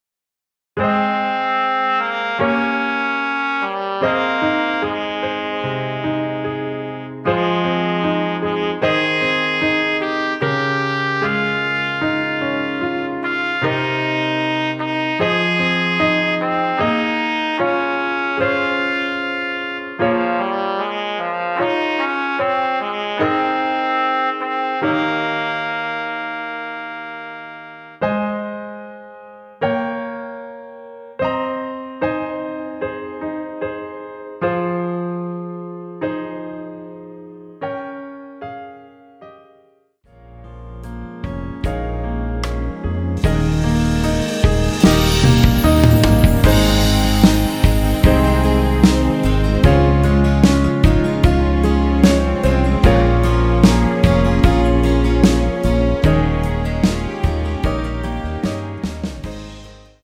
원키에서(-4)내린 MR입니다.
Ab
앞부분30초, 뒷부분30초씩 편집해서 올려 드리고 있습니다.